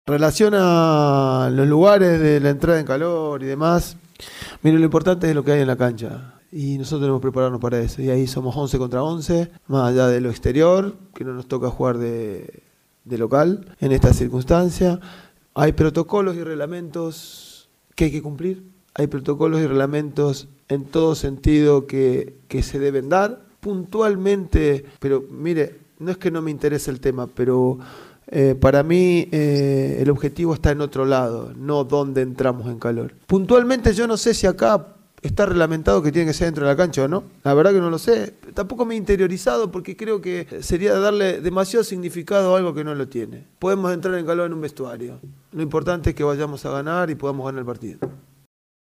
El entrenador de Universidad de Chile, Frank Darío Kudelka, se refirió en conferencia de prensa al Superclásico 184 del fútbol chileno ante Colo Colo, señalando que como equipo necesitan el triunfo, para sacarse la negativa historia de 17 años sin ganar en el Monumental.